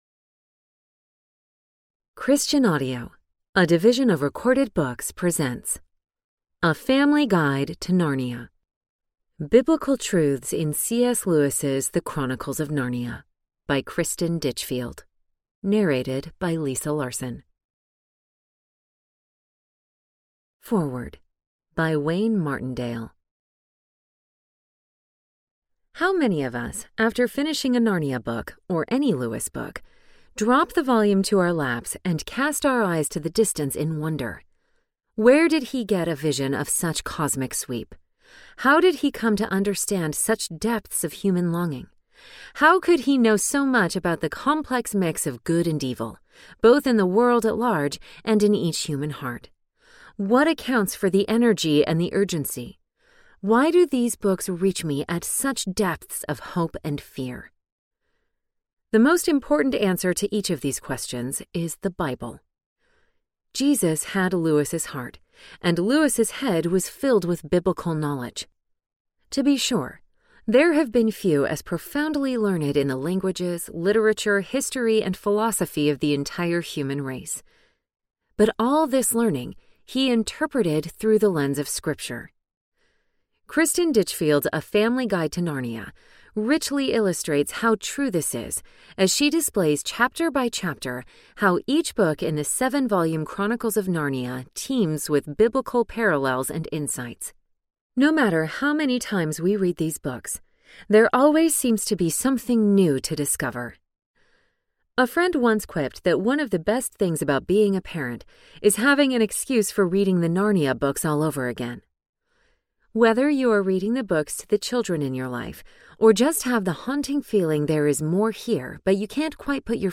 A Family Guide to Narnia Audiobook
Narrator
6.6 Hrs. – Unabridged